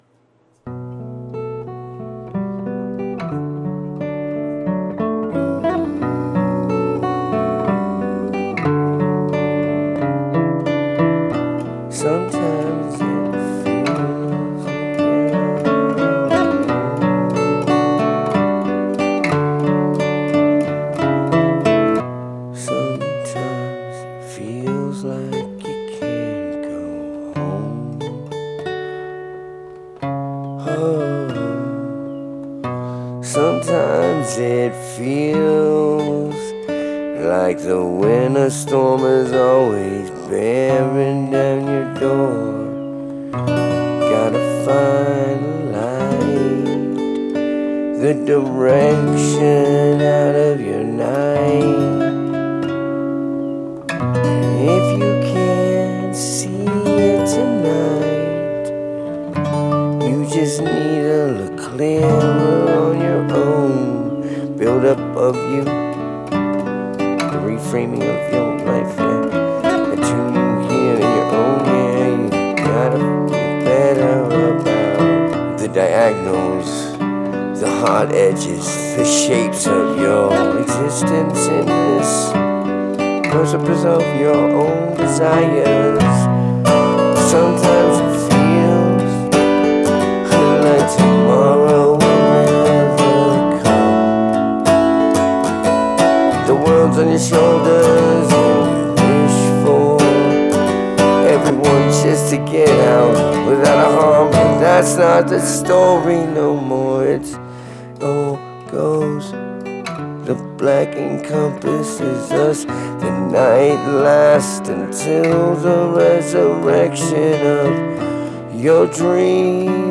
Date: 2026-07-09 · Mood: dark · Tempo: 62 BPM · Key: C major